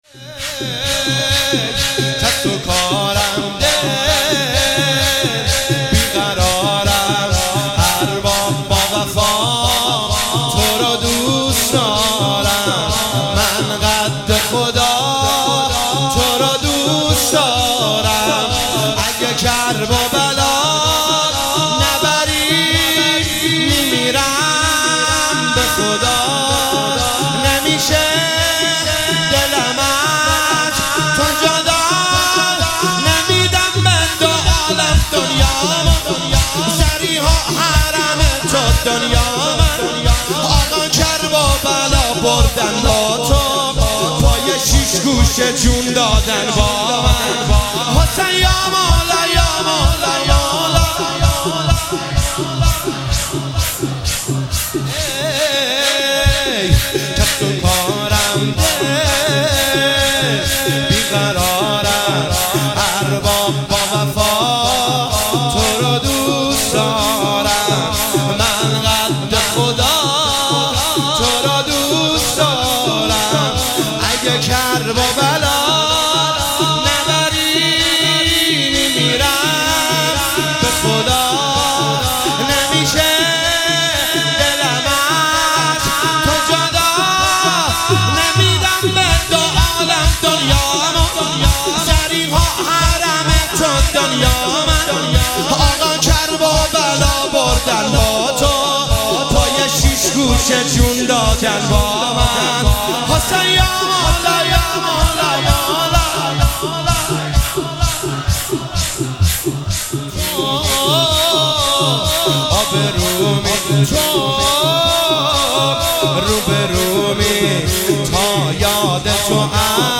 شهادت حضرت رقیه(س) |هیئت ام ابها قم